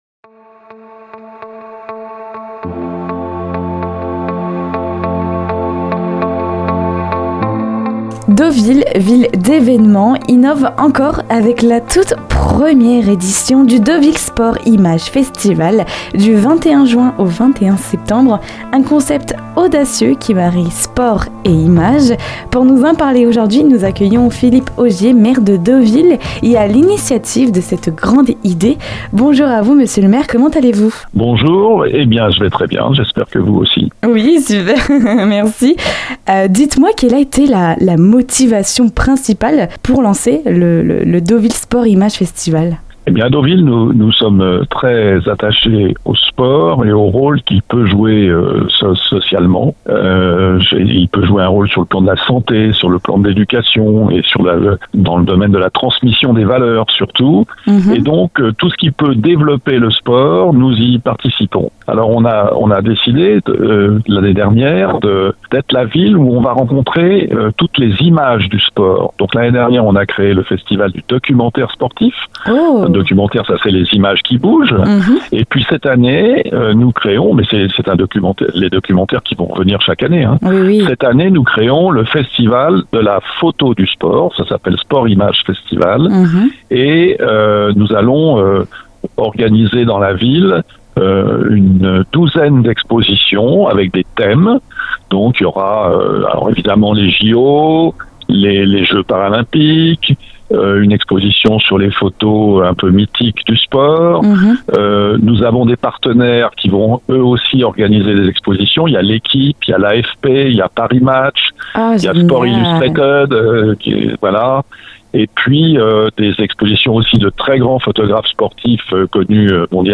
Nous avons eu le plaisir d'accueillir Philippe Augier, Maire de Deauville.
Pour en savoir plus sur cet rencontre n'hésitez pas à écouter jusqu'au bout l'interview et à partir flâner à Deauville pour découvrir ces expositions !